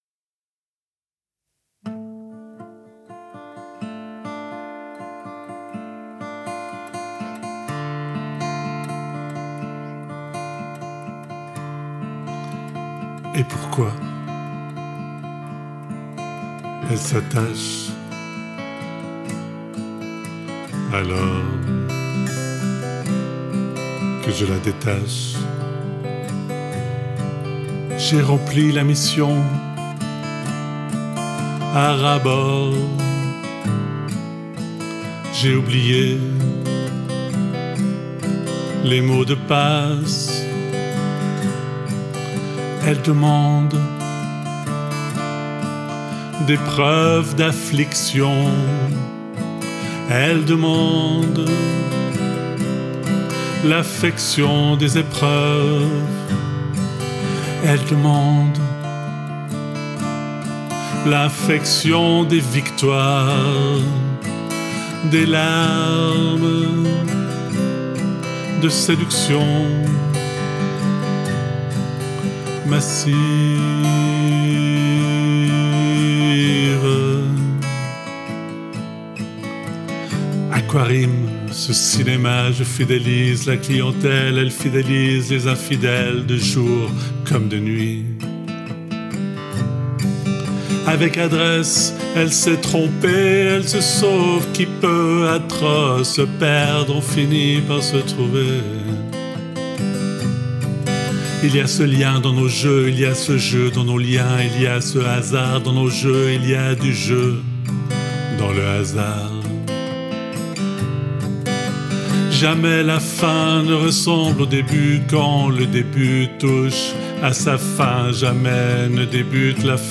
guitare, voix